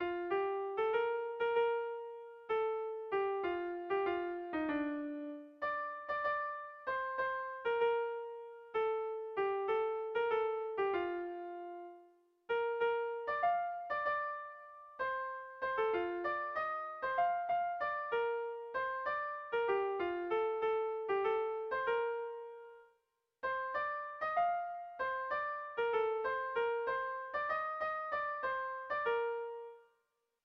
Dantzakoa
Zortziko txikia (hg) / Lau puntuko txikia (ip)
ABDE